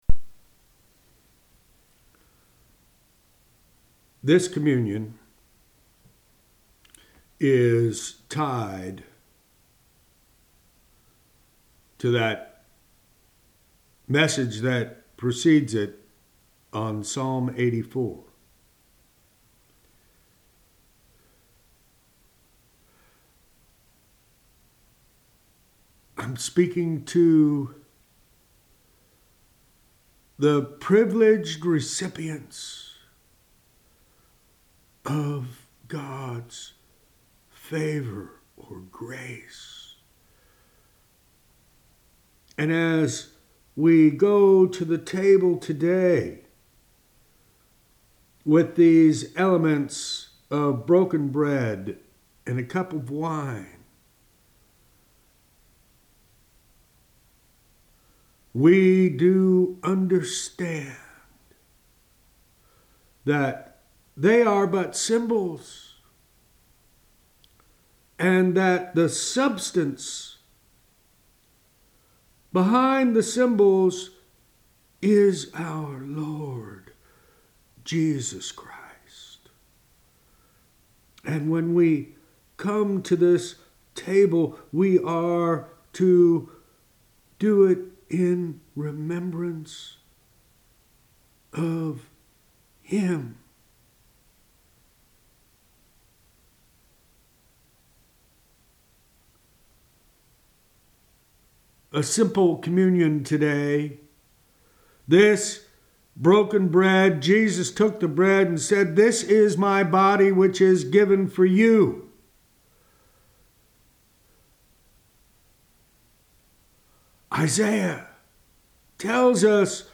Communion Teaching